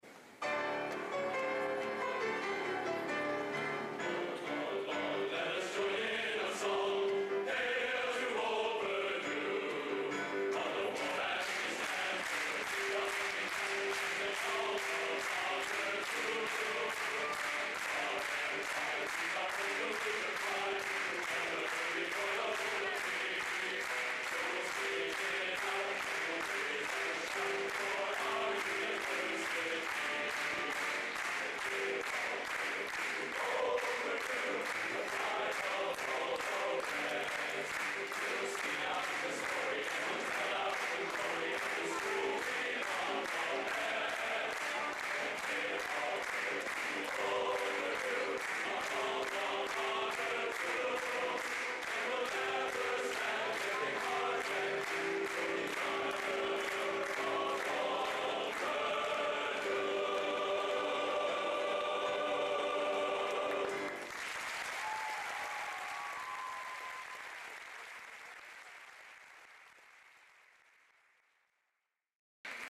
Location: Purdue Memorial Union, West Lafayette, Indiana
Genre: Collegiate | Type: